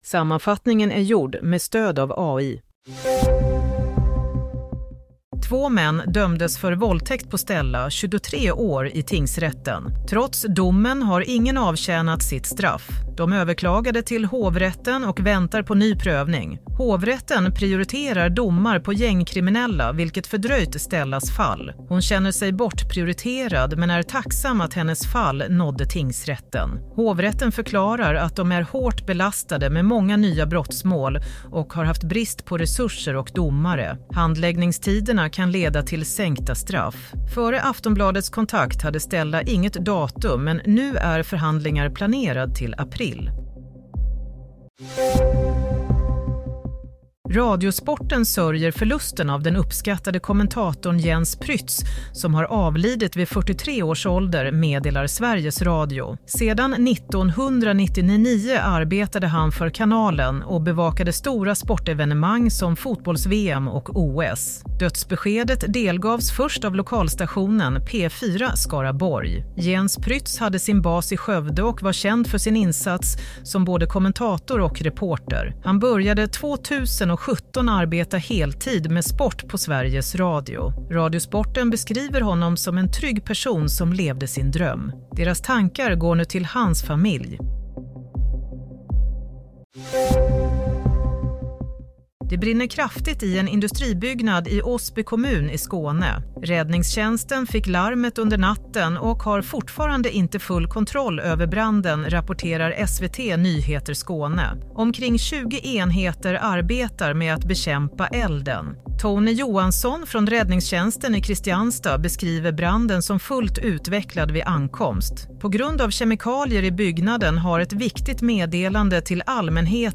Nyhetssammanfattning - 6 mars 07:00